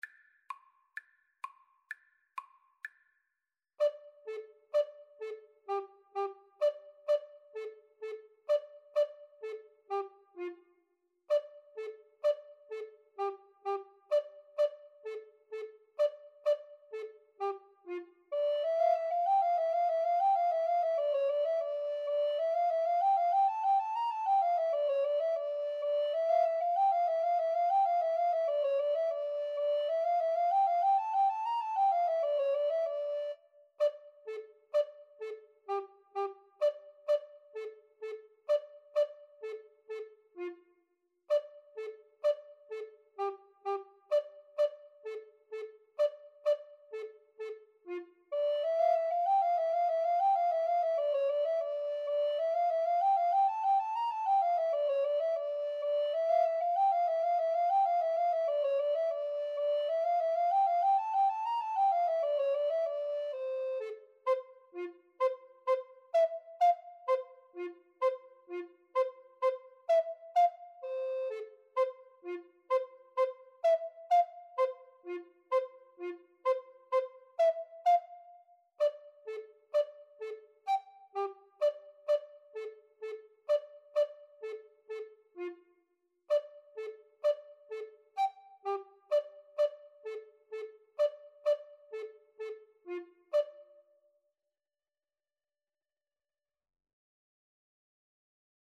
Play (or use space bar on your keyboard) Pause Music Playalong - Player 1 Accompaniment reset tempo print settings full screen
D minor (Sounding Pitch) (View more D minor Music for Alto Recorder Duet )
6/8 (View more 6/8 Music)